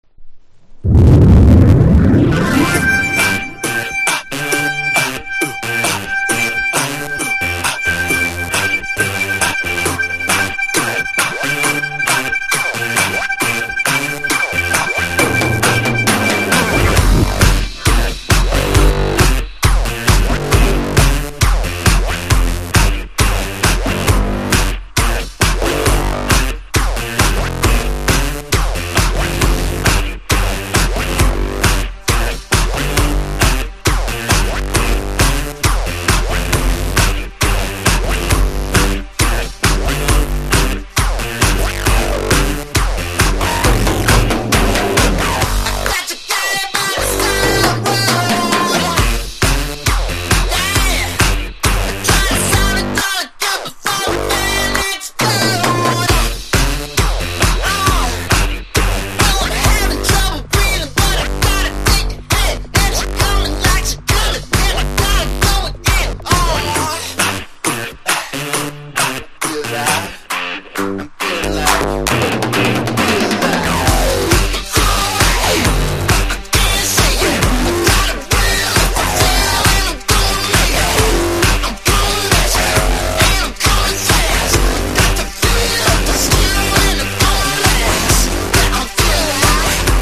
前作からうって変ってアタックの強いエレクトロ色濃い作品！！